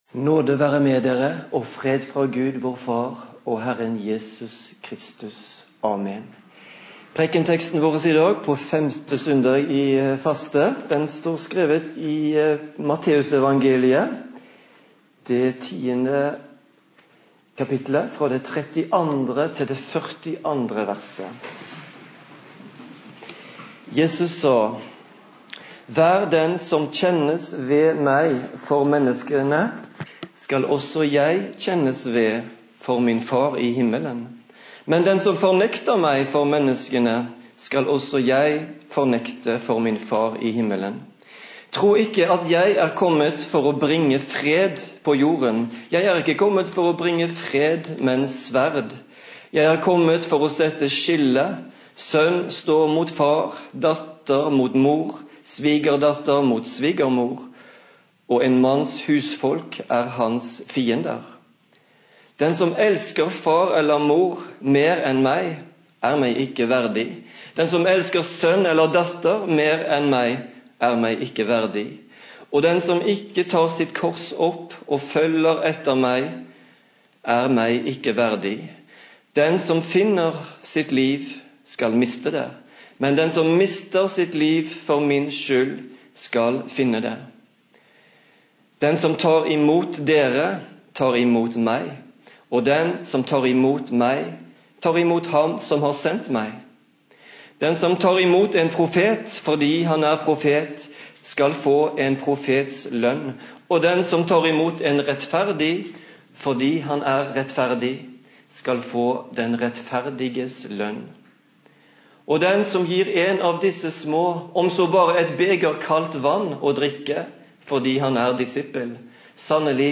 Preken på 5. søndag i faste